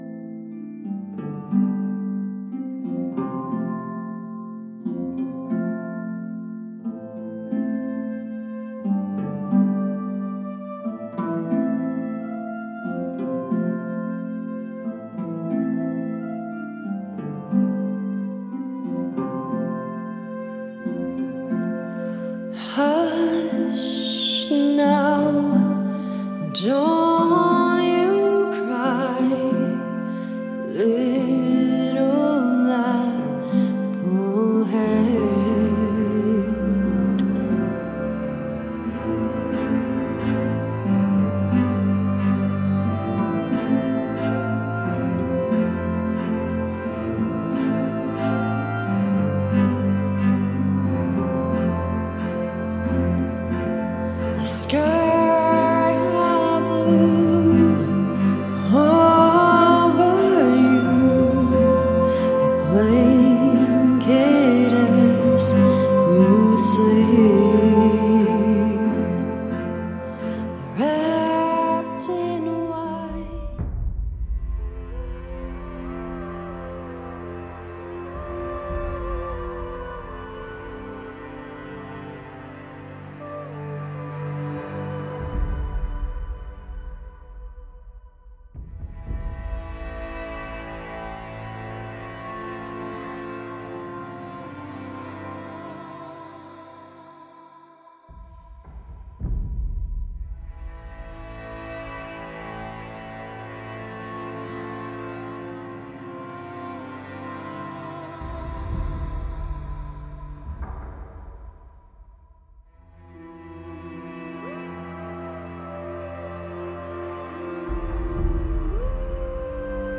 world, folk and celtic to gothic rock and industrial metal